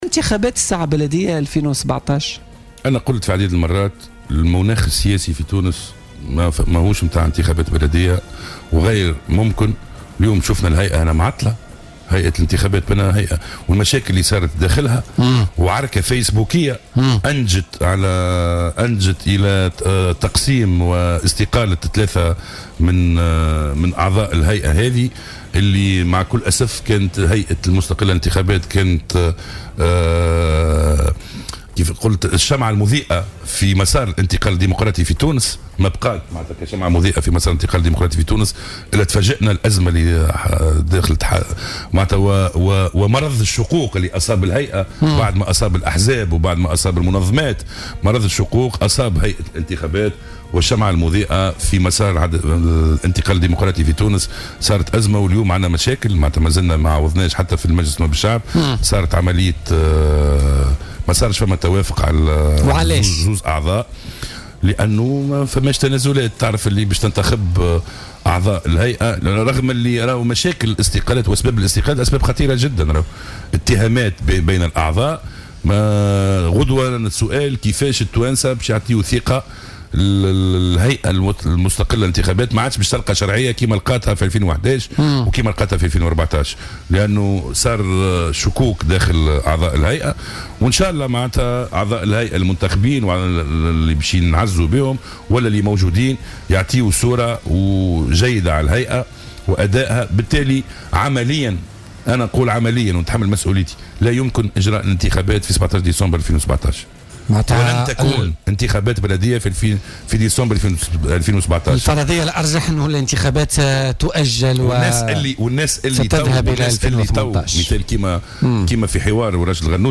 واعتبر ضيف "بوليتيكا" على "الجوهرة أف أم" أن لا يمكن اجراء هذه الانتخابات عمليا وواقعيا في شهر مناقشة قانون المالية.